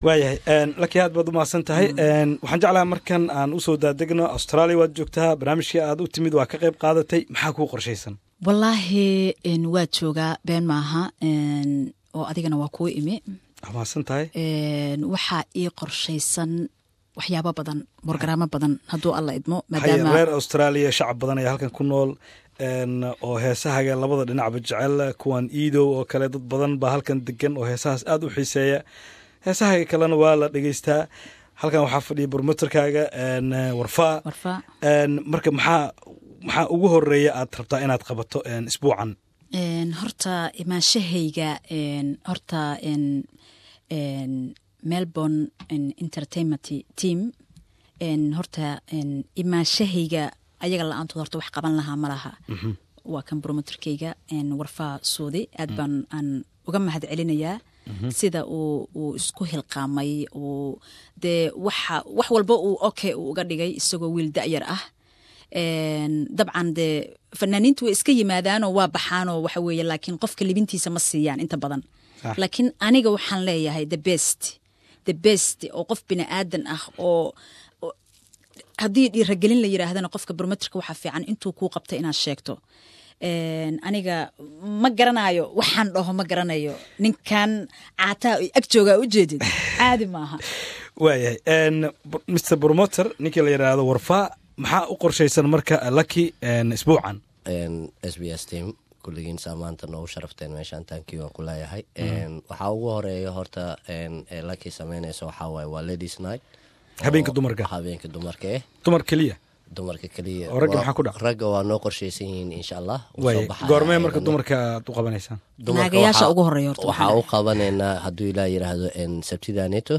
Qeyta Sedexaad Wareysi iyo Heeso